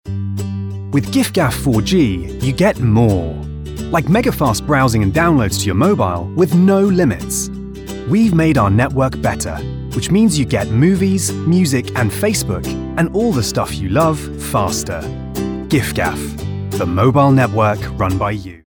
His voice is naturally conversational but also has an authoritative tone to it.